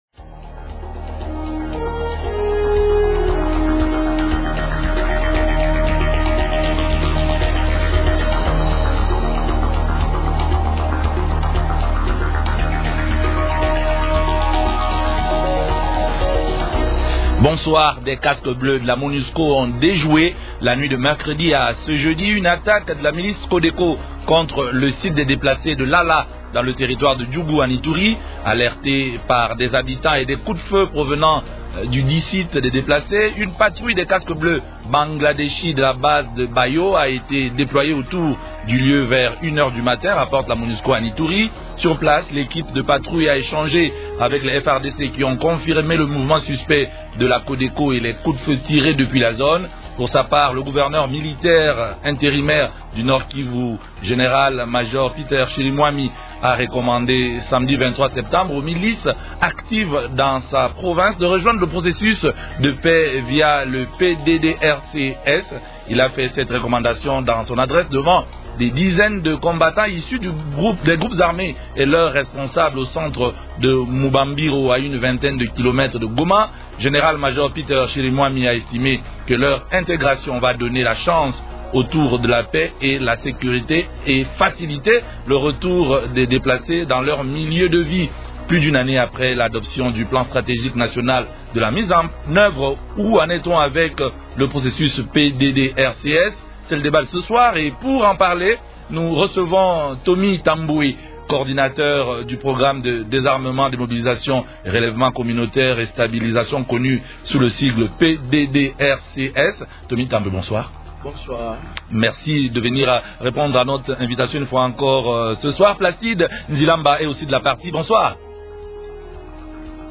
-Plus d’une année après l'adoption du plan stratégique national de sa mise en œuvre, ou en est-on avec le processus PDDRC-S ? Invités : -Tomy Tambwe, coordinateur du Programme de désarmement, démobilisation, relèvement communautaire et stabilisation (PDDRC-S).